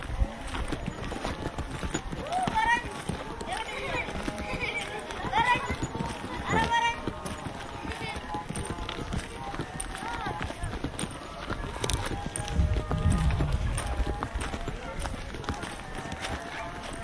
描述：区的孩子们，结束了德布雷比勒姆埃塞俄比亚2006年1月MD录制的
标签： 氛围 儿童 埃塞俄比亚 晚上 一月 德勃雷 beirham 2006年
声道立体声